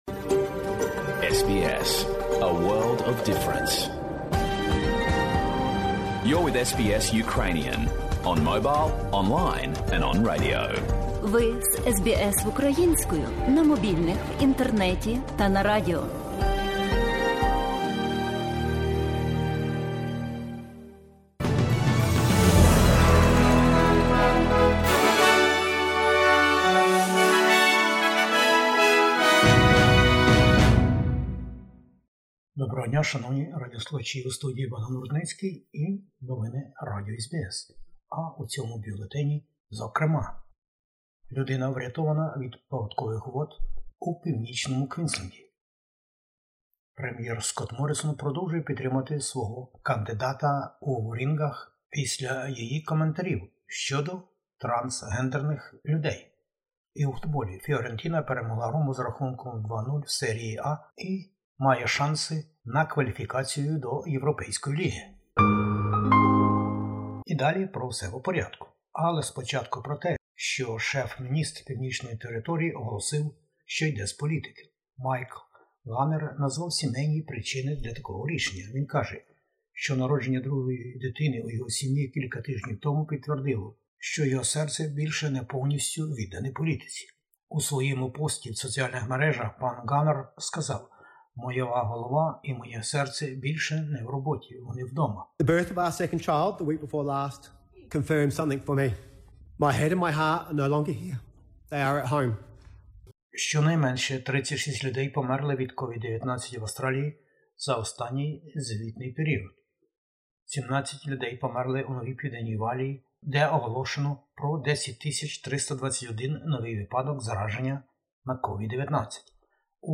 Бюлетень SBS новин українською мовою. Федеральні вибори-2022 - дострокове голосування уже розпочалося по всій країні.